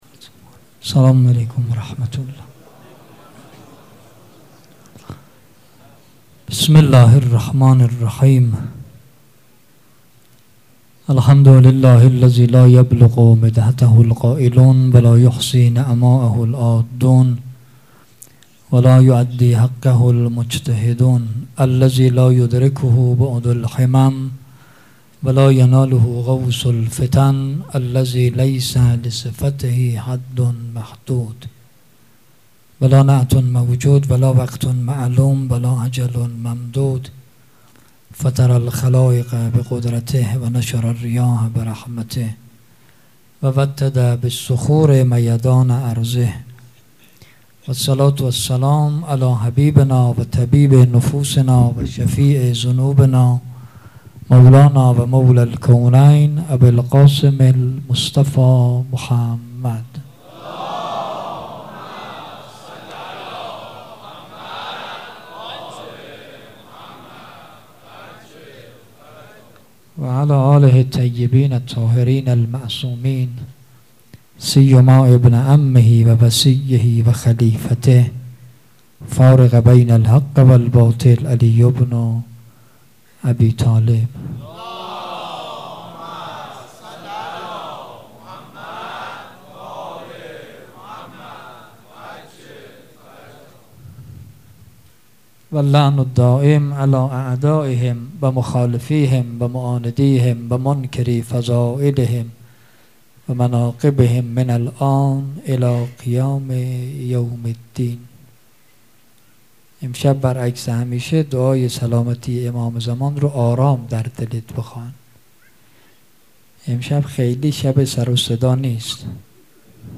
سخنرانی
شب عاشورا
مراسم عزاداری شب عاشورا